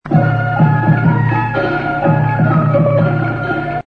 AKAI GX 636 umgebaut für Magnettonwiedergabe
Aus unserem Filmfundus haben wir eine 120 Meter Super8 Filmspule mit Stereoton von Weltreiseaufnahmen aus den 70er Jahren herausgesucht und 12 kurze Samples zum kurzen Anspielen für Sie angefertigt: